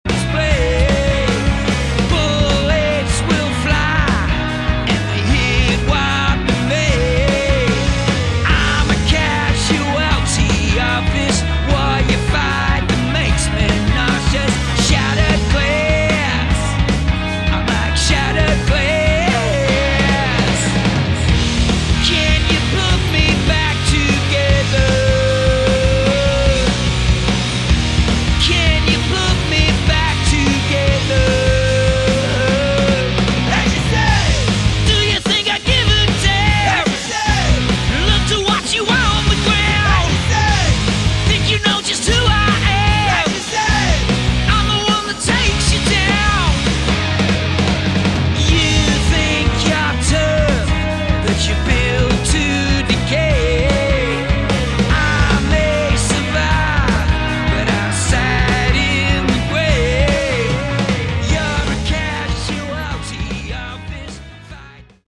Category: Sleazy Hard Rock
vocals
electric & acoustic guitars, Mellotron
bass
drums (studio), strings